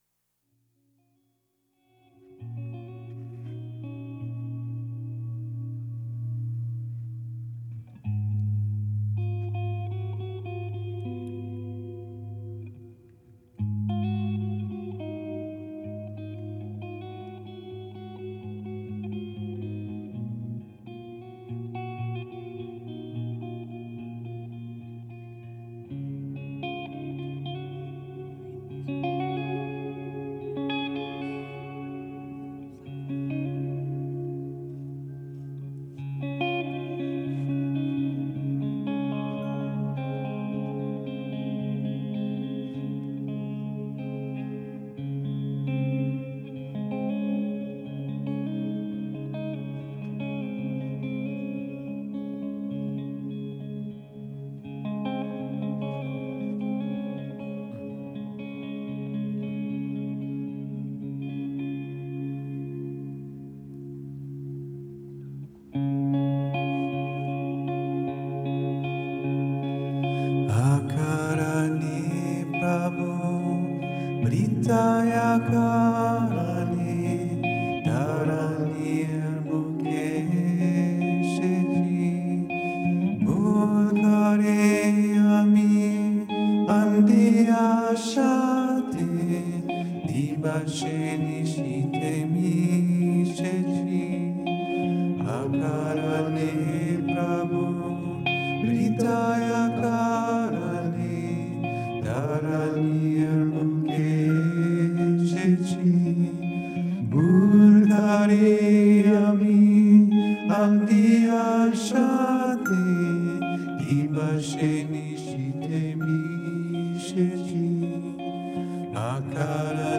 Poetry and Music performances from Father’s Day celebrations | Radio Sri Chinmoy
Dear listeners, it is with great joy that we bring you three selected performances from our Fathers’ Day Celebration at Aspiration-Ground in New York.